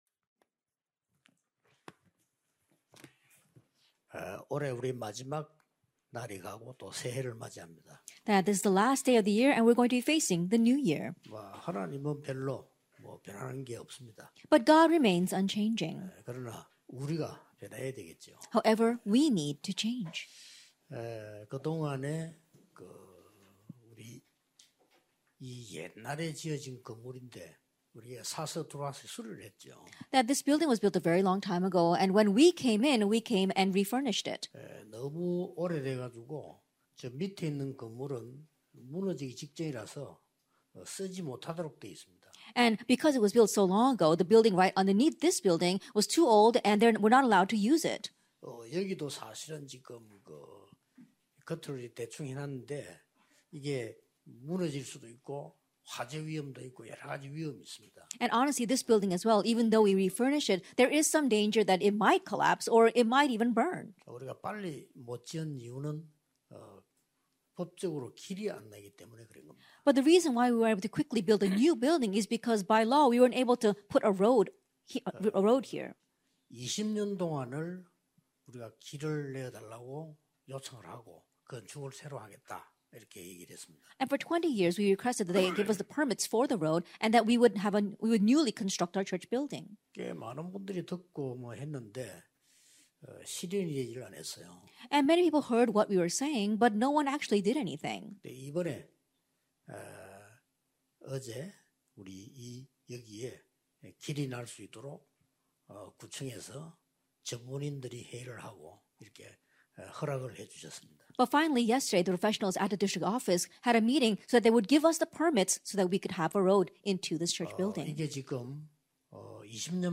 2022 New Year's Prayer Meeting Lec. 1 - My 24hrs